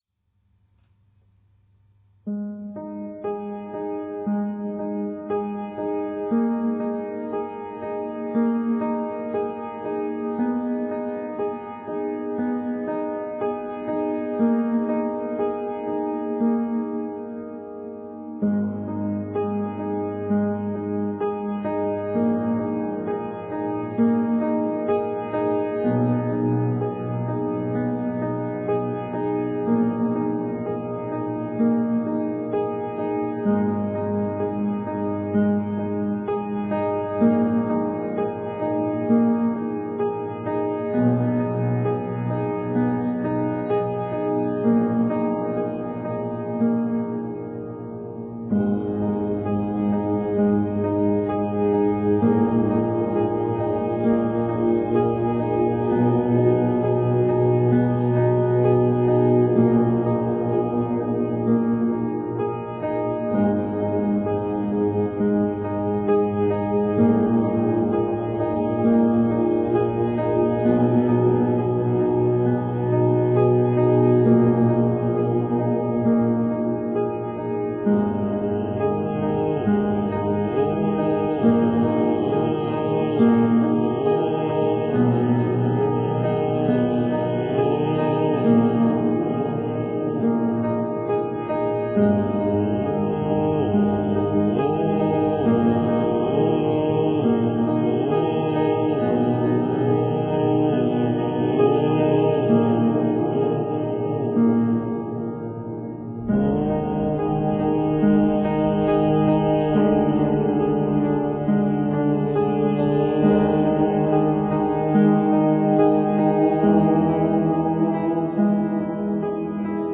*фонова композиція –